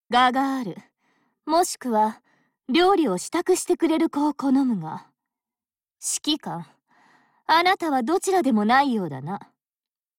川澄 绫子 / かわすみ あやこ / Ayako Kawasumi / 事务所个人介绍页
舰船台词